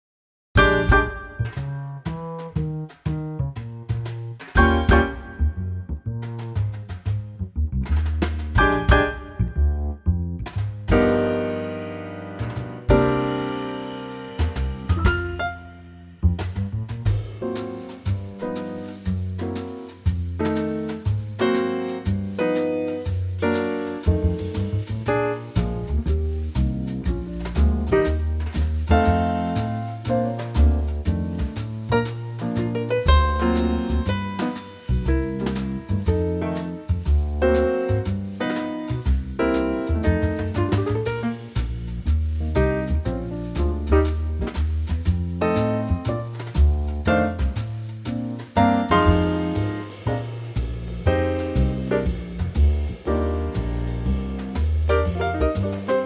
key - Bb - vocal range - F to Ab
Superb Trio arrangement
-Unique Backing Track Downloads